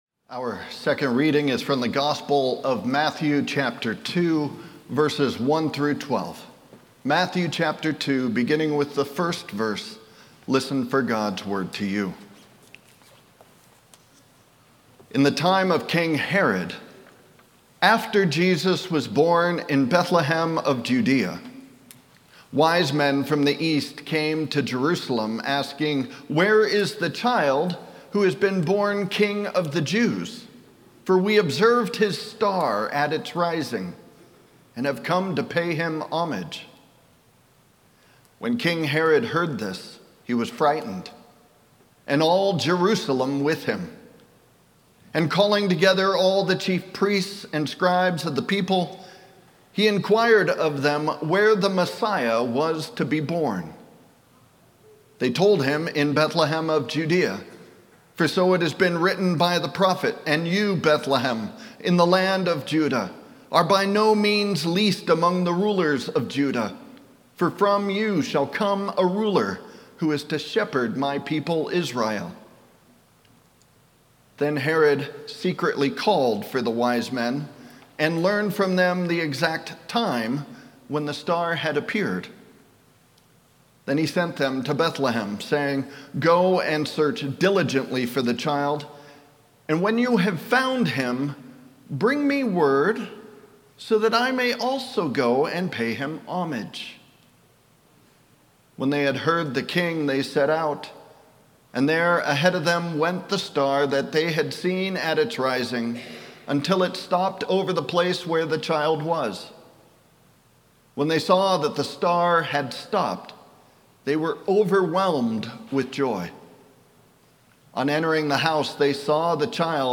Audio Sermons details
Sermon+1-4-26.mp3